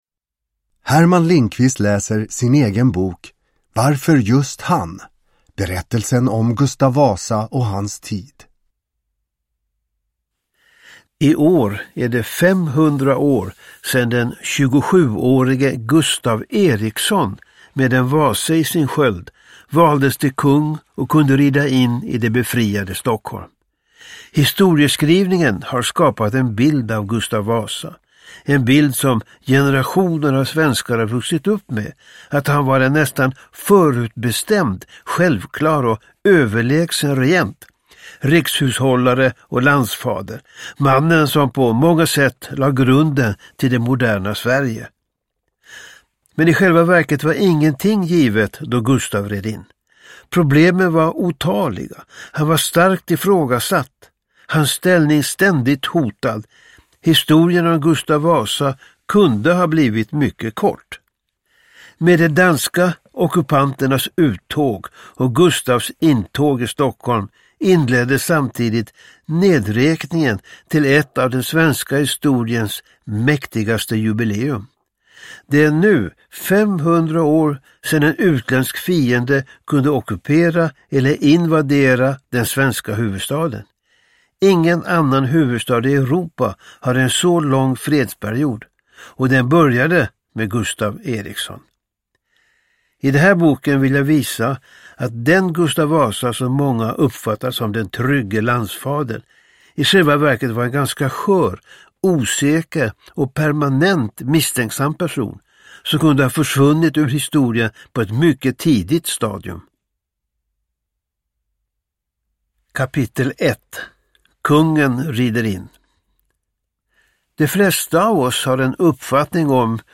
Uppläsare: Herman Lindqvist